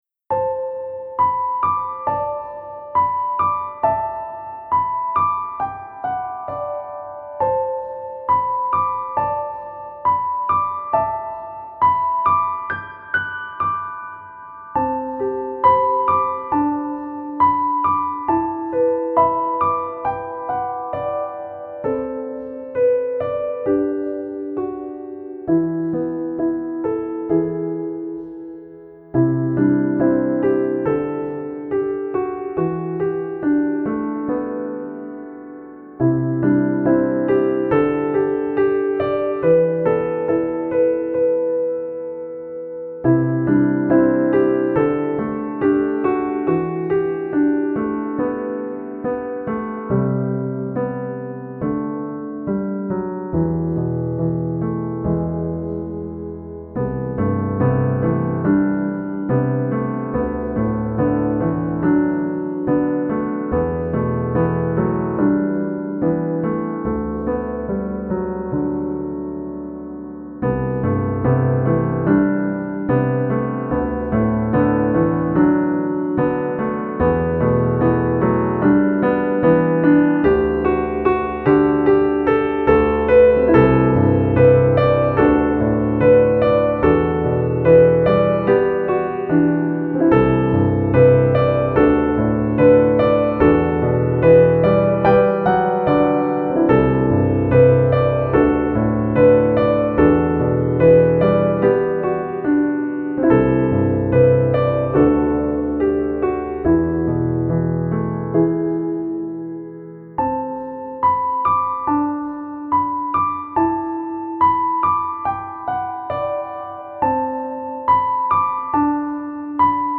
原曲
テンポ：♩=free
主な使用楽器：ピアノ